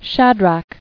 [Shad·rach]